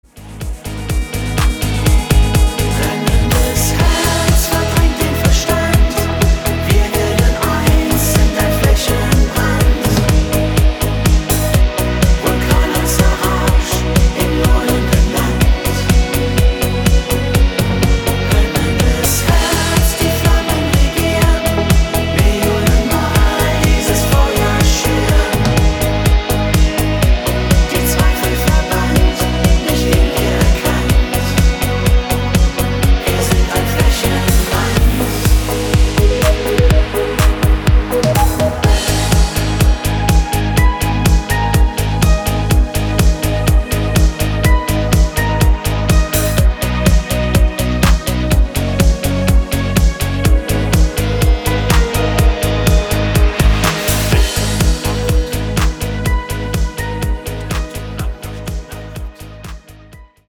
Discofox vom aller Feinsten